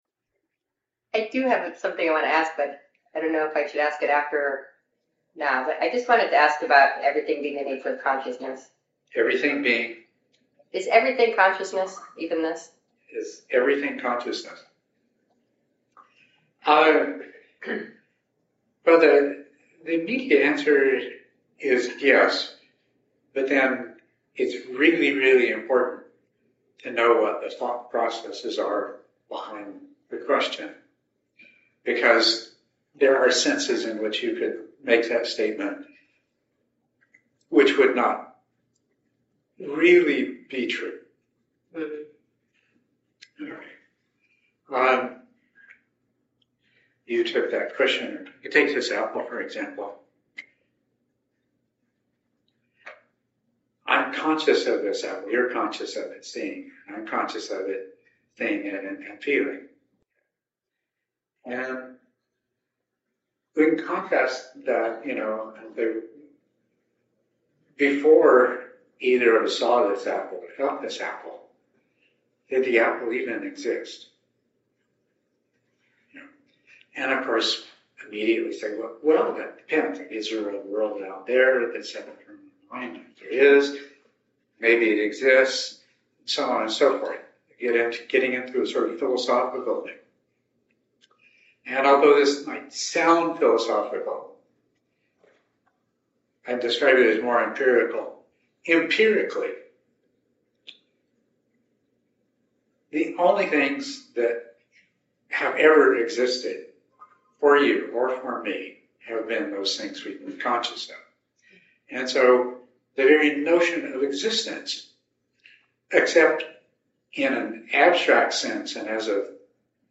Q & A
Edit talk Download audio (mp3) Download original audio Listen to original audio * Audio files are processed to reduce background noise, and provide (much) better compression.